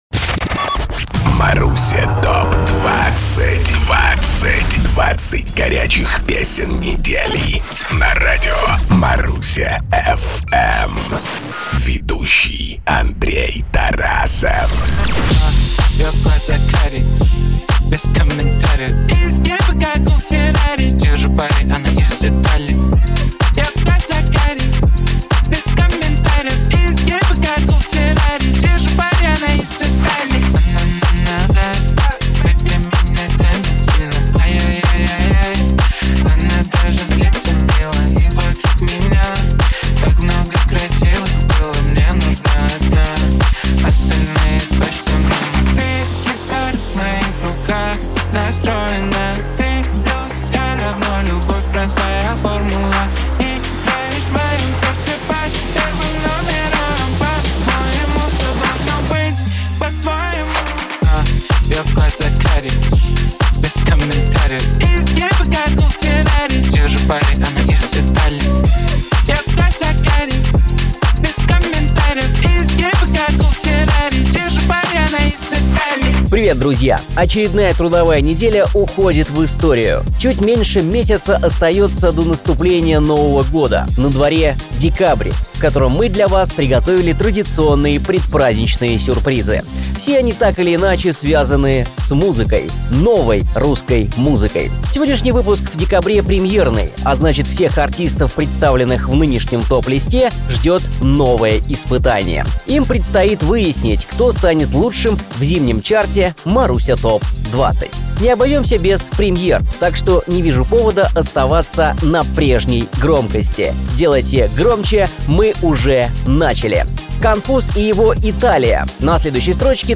20 хитов за 20 минут!